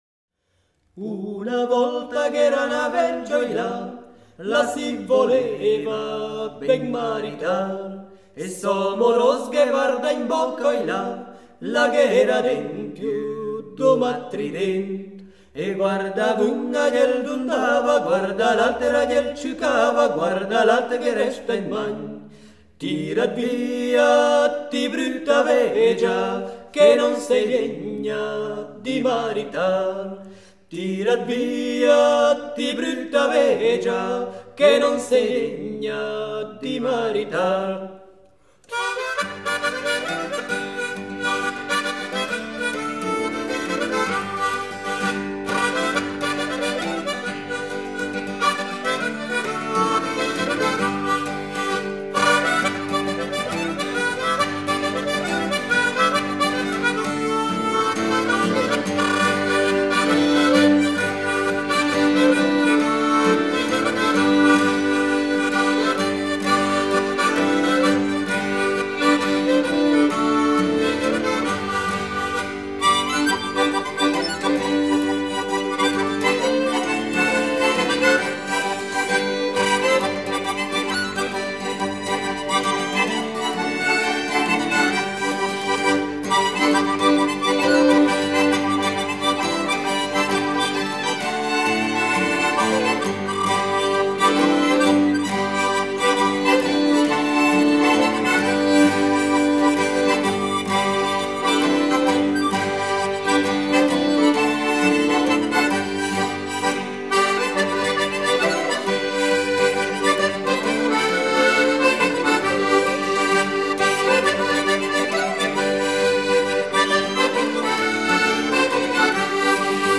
Ticino: Genuine Folk Music from Southern Switzerland